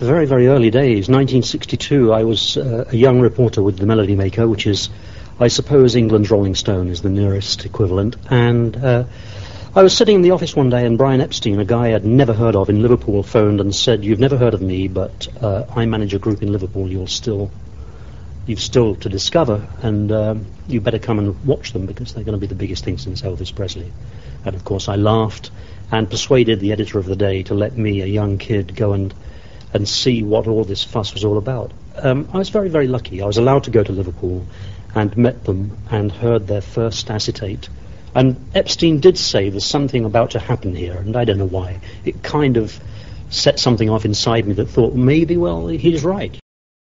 Here are a few never before seen extracts from those interviews: